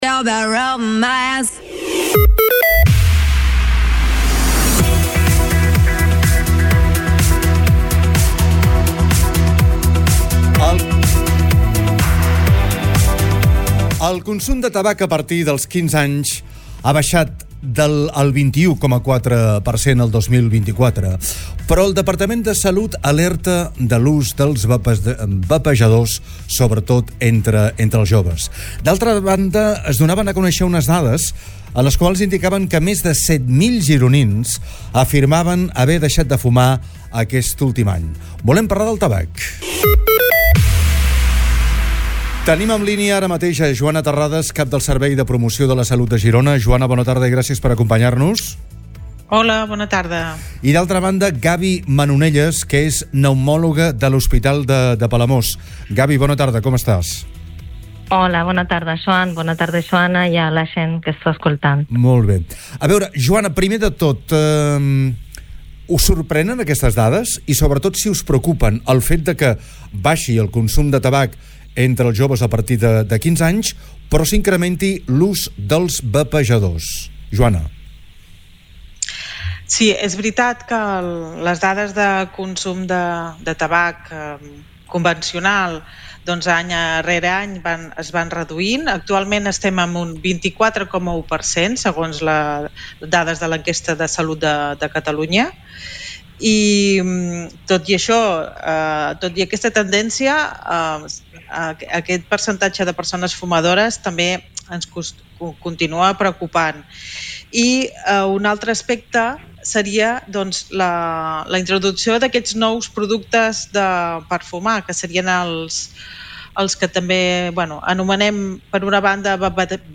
han entrevistat